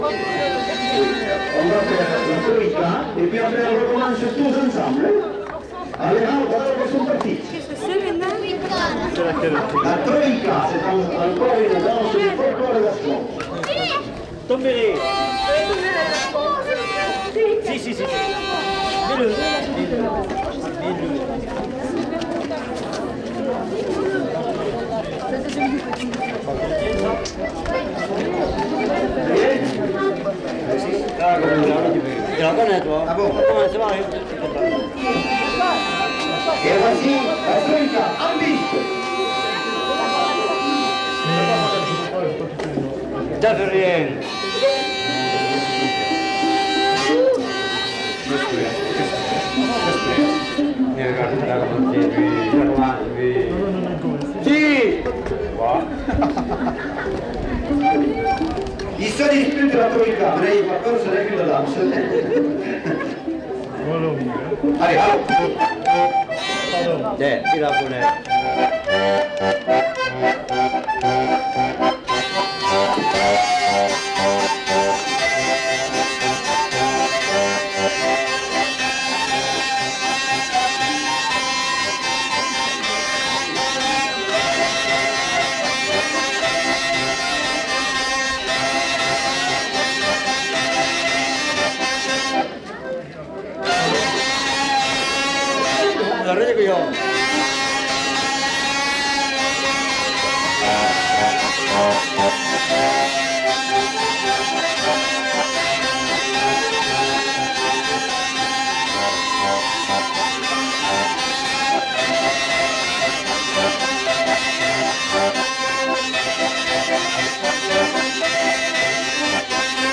Aire culturelle : Gabardan ; Néracais
Lieu : Brax
Genre : morceau instrumental
Instrument de musique : vielle à roue ; accordéon diatonique
Danse : troïka